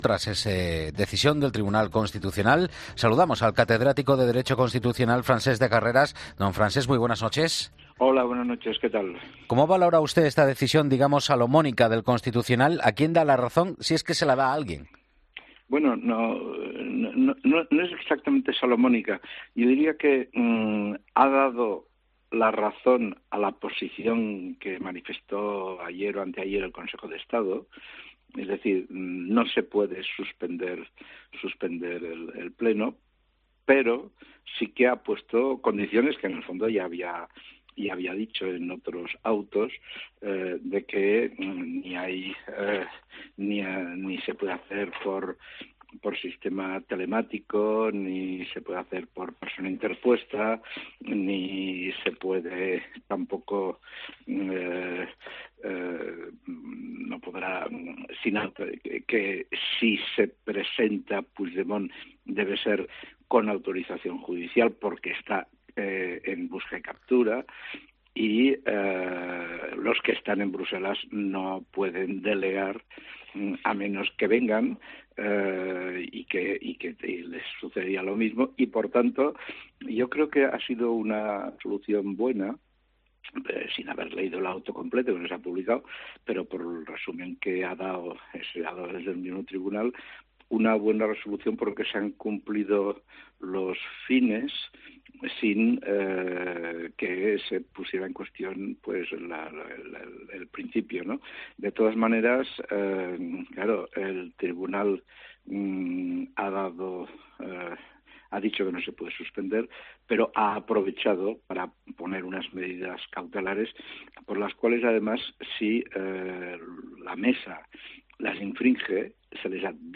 El catedrático en Derecho Constitucional, Francesc de Carreras, ha destacado en 'La Linterna' que la decisión del Tribunal Constitucional (TC) es una "buena resolución" y "muy inteligente".
En tono de humor, el catedrático dice esta resolución "evita que el señor Puigdemont aparezca por una alcantarilla cercana al Parlament".